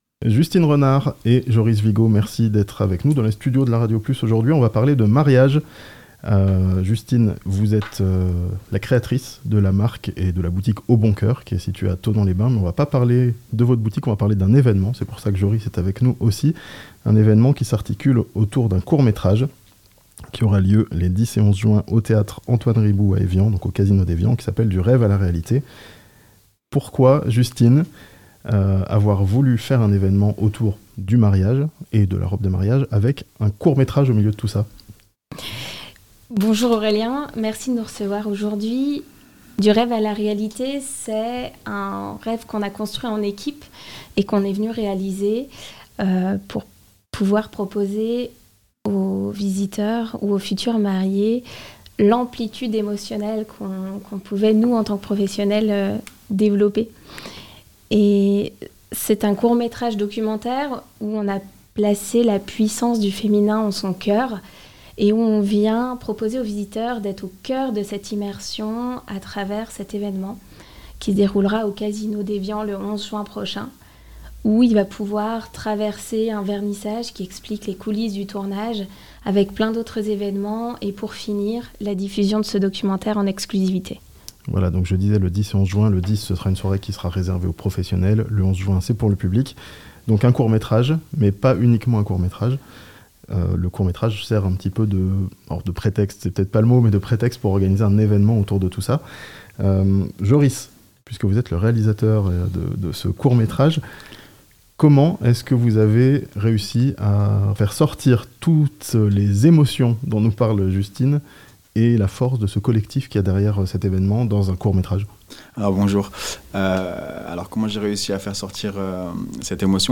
A Evian, une soirée évènement immersive, avec un court métrage autour du mariage (interview)